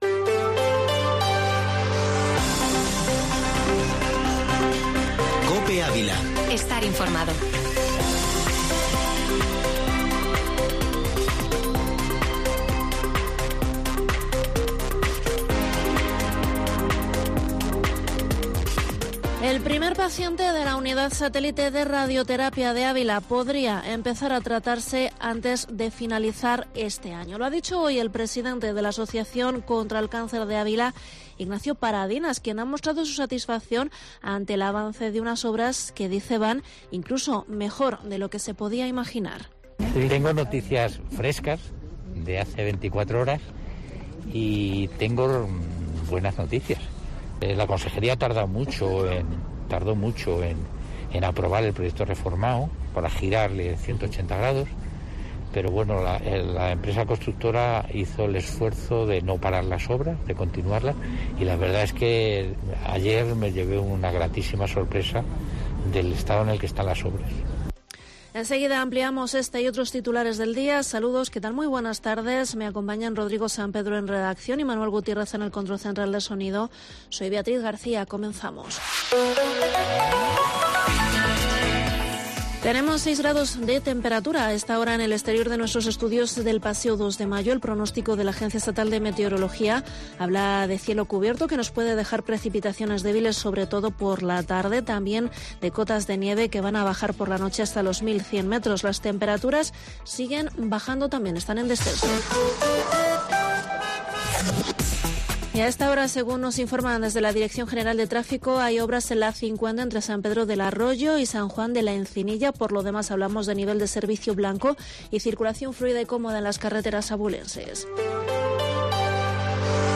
Informativo Mediodía Cope en Avila 31/3/22